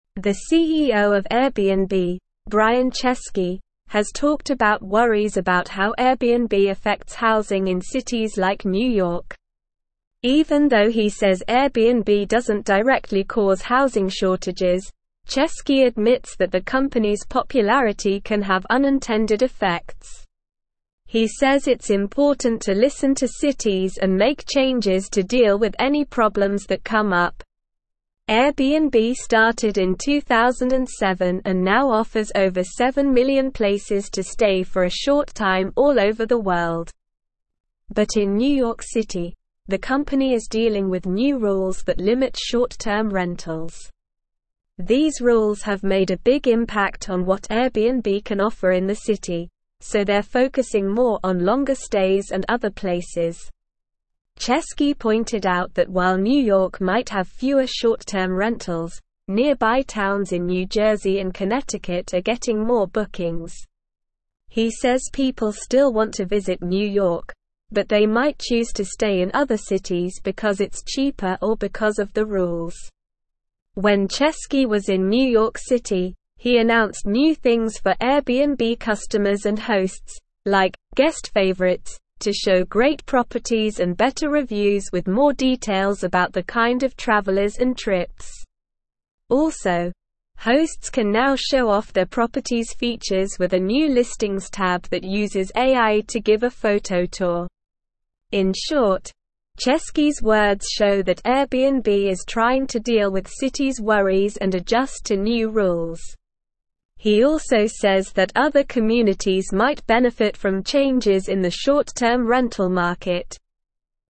Slow
English-Newsroom-Upper-Intermediate-SLOW-Reading-Airbnb-CEO-Addresses-Housing-Concerns-Adapts-to-Regulations.mp3